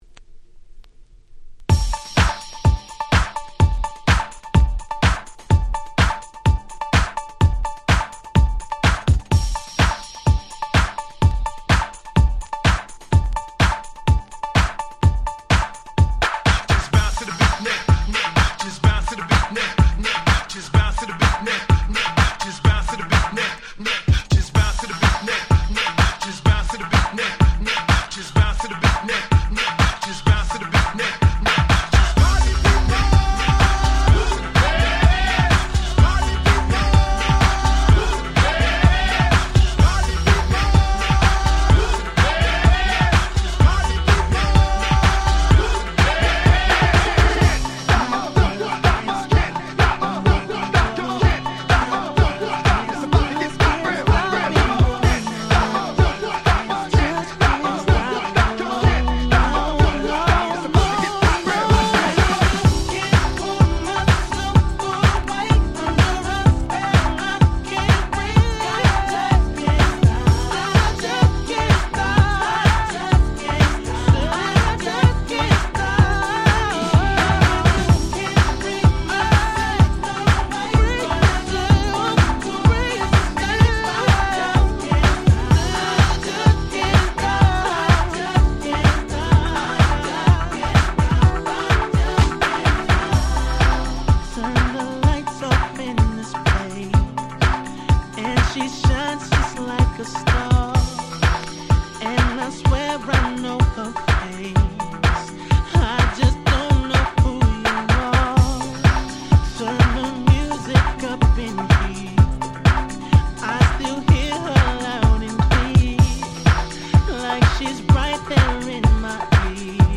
White盤オンリーのアゲアゲParty Tracks/勝手にRemix物！！